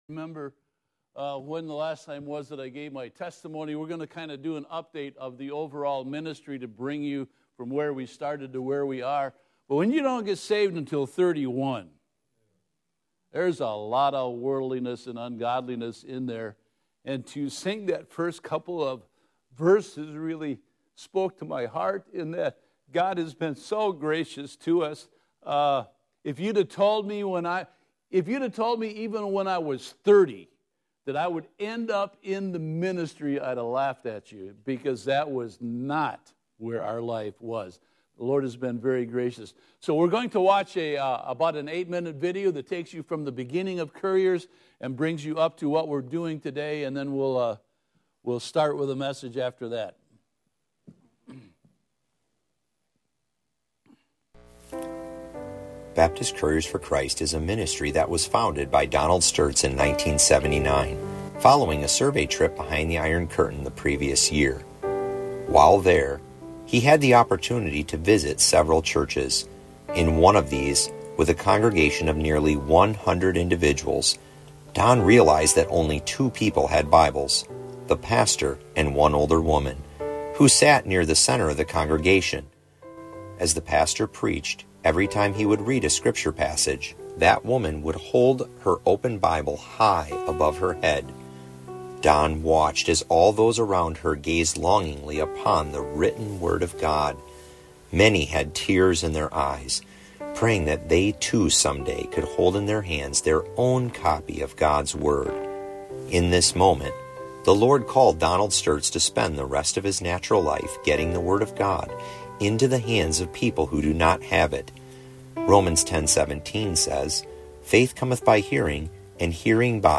What Is God’s Reaction To Wickedness?Missionary Report and Message